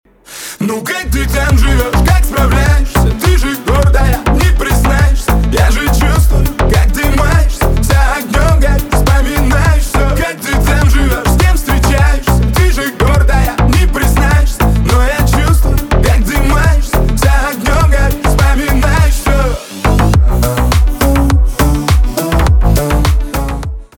поп
битовые , басы